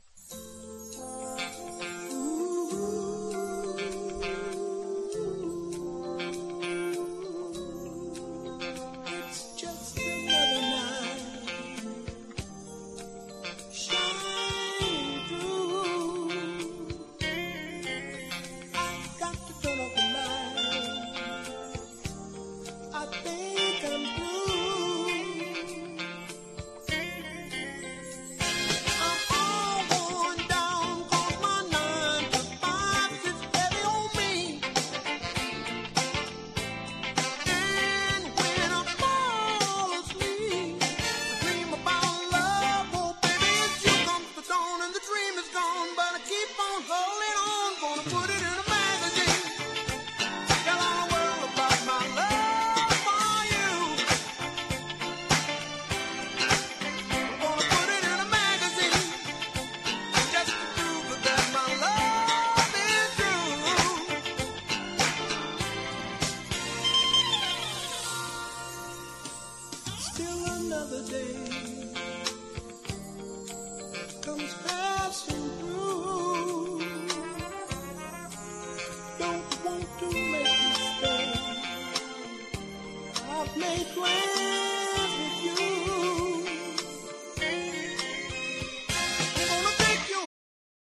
# NORTHERN / MODERN